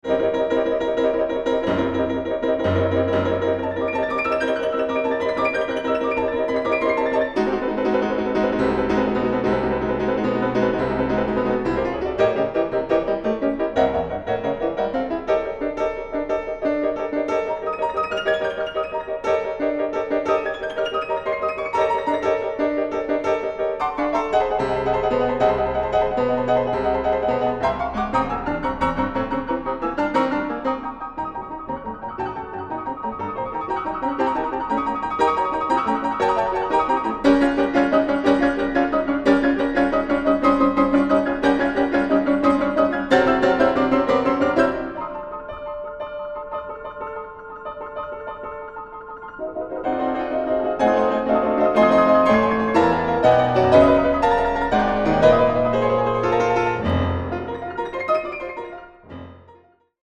Klavier
Aufnahme: Mendelssohnsaal, Gewandhaus Leipzig